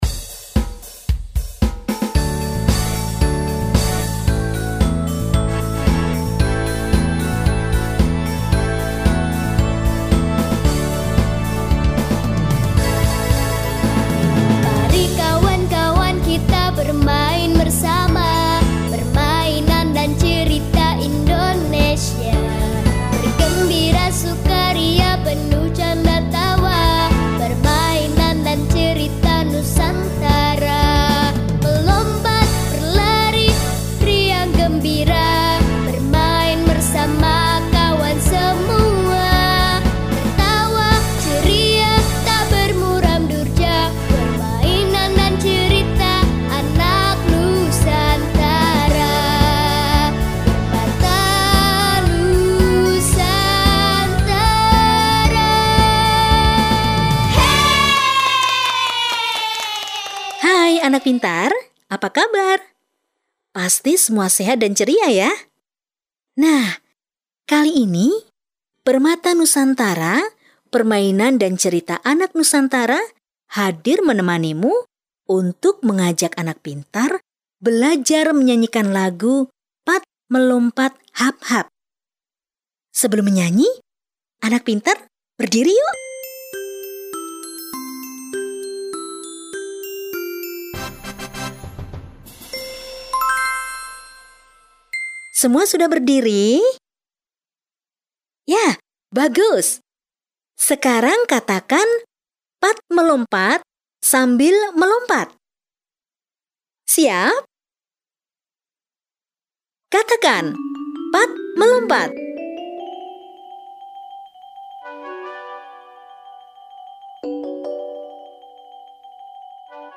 Audio - Pat Melompat Hap Hap 2 Lagu Permainan